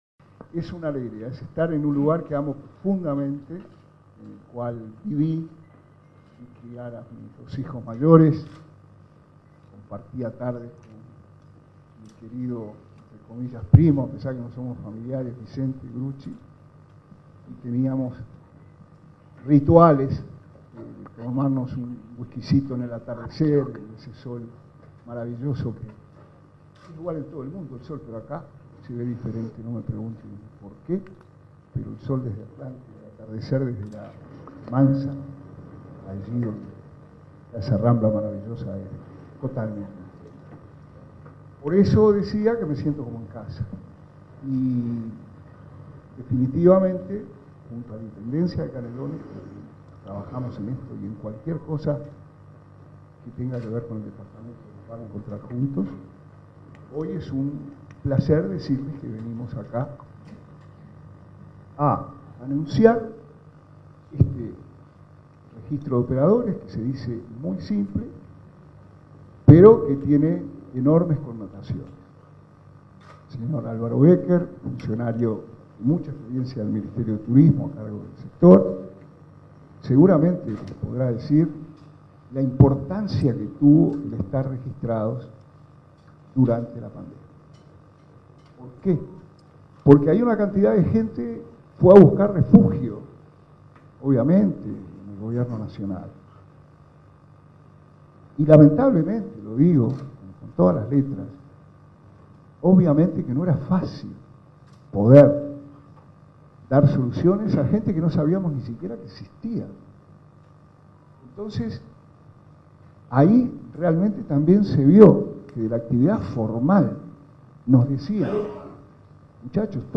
Palabras del ministro interino de Turismo, Remo Monzeglio
El ministro interino, Remo Monzeglio, participó del evento.